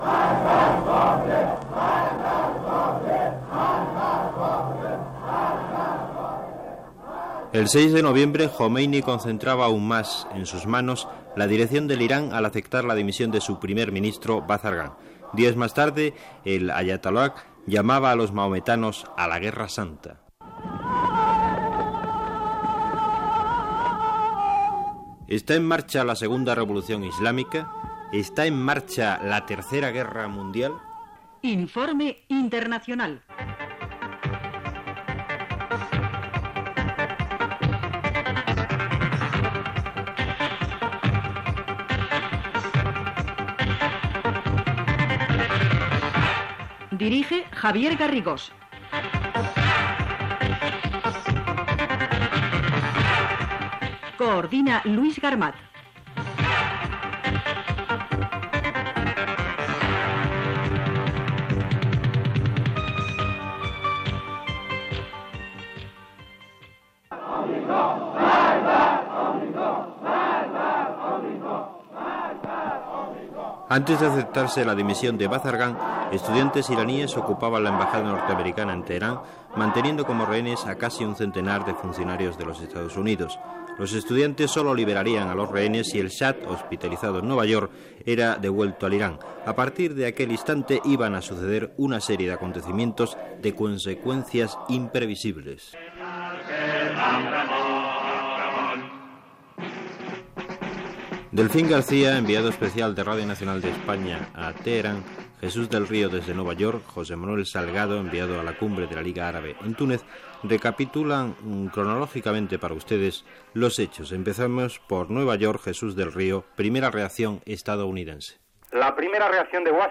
Reportatge sobre la revolució islàmica a l'Iran que va començar amb l'assalt d'un grup d'estudiants islàmics a l'ambaixada dels EE.UU. a Teheran (Iran) el 3 de novembre de 1978. Cronologia dels fets de 1978 i 1979
Informatiu